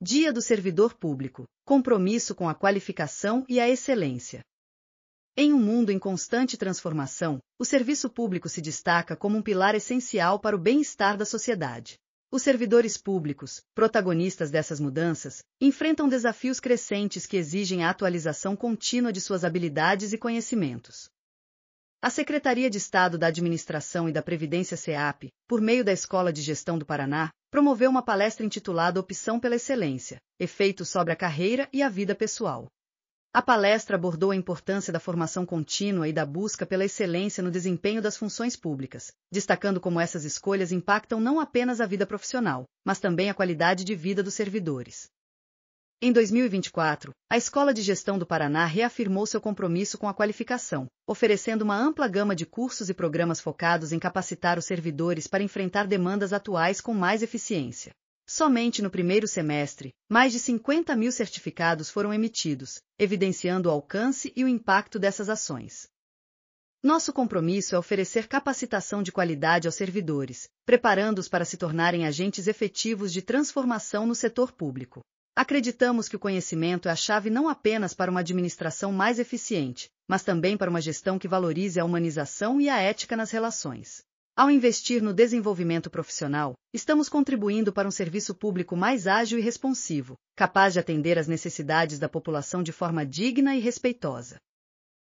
audionoticia_dia_do_servidor_publico.mp3